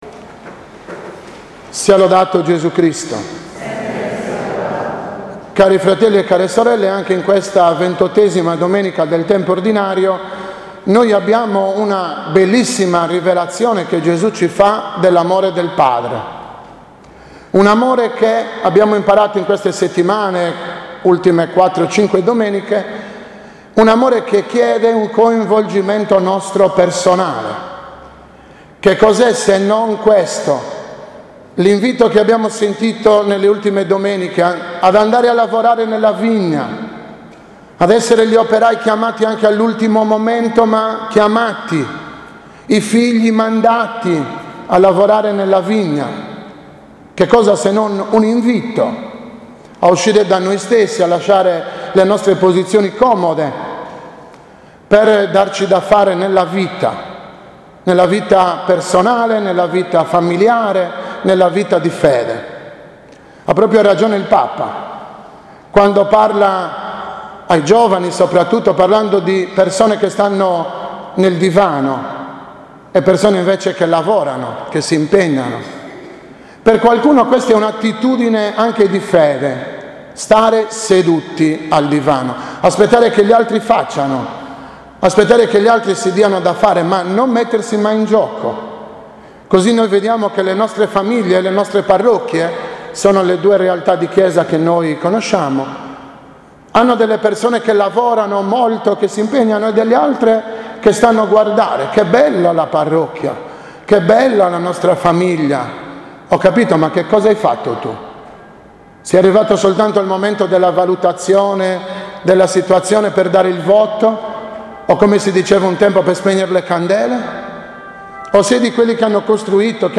15.10.2017- OMELIA DELLA XXVIII DOMENICA PER ANNUM A
omelia-XXVIII-PER-ANNUM-A.mp3